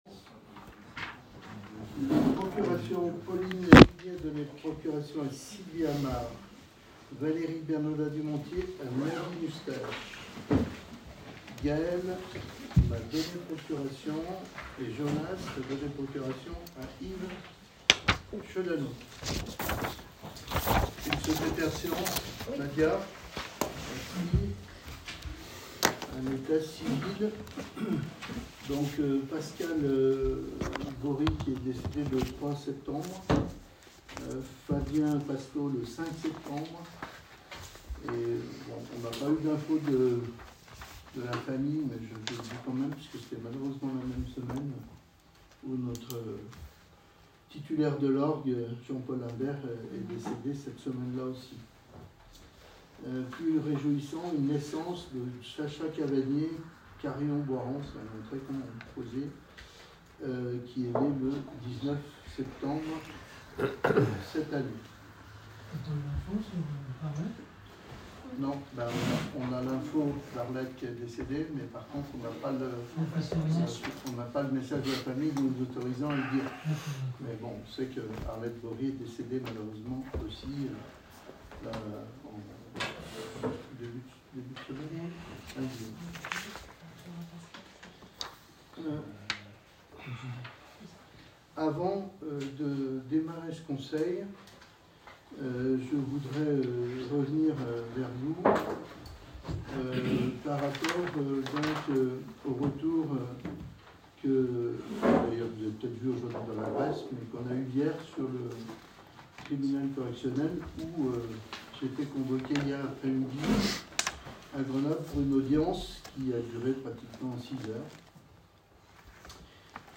CONSEIL MUNICIPAL DU 20 AOÛT 2025
Pour écouter le conseil dans son intégralité, c’est ici.